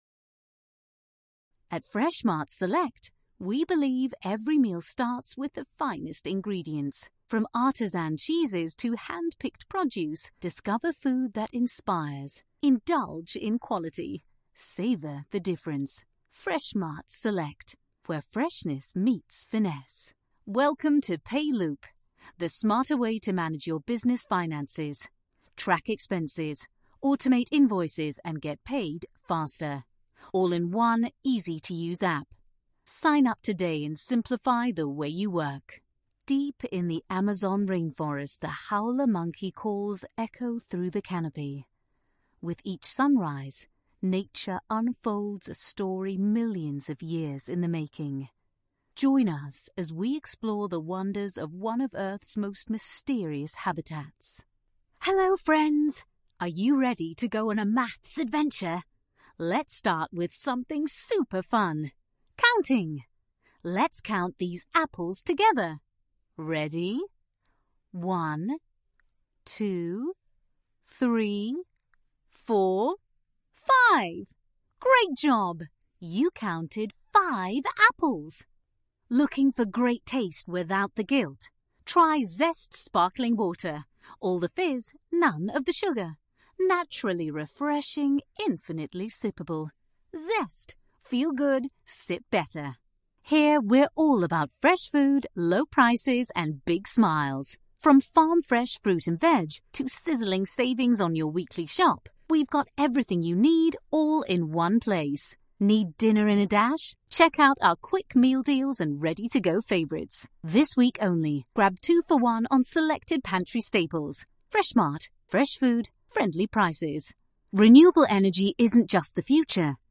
English (Scottish) voice actor